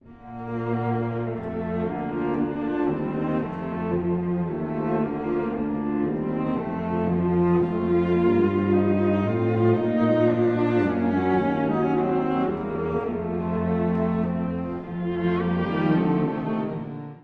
↑古い録音のため聴きづらいかもしれません！（以下同様）
穏やか、しかし情熱的な楽章です。
この楽章は、中低音と高音のメリハリが付いており、ひじょうに聴きやすいです。
提示部は、中低音の柔らかい響きが魅力。
また、裏で波打つような8分音符も特徴的です。